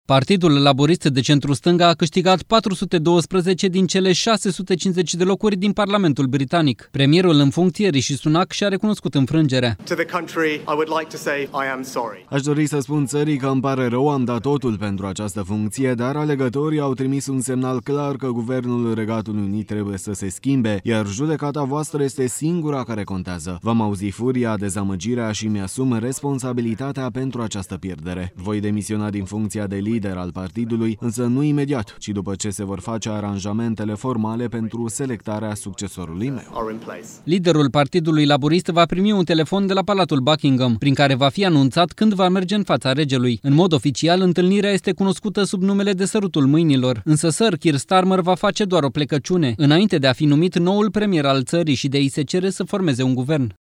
“Îmi pare rău”, a declarat fostul premier al Marii Britanii, pe treptele de la Downing Street, numărul 10.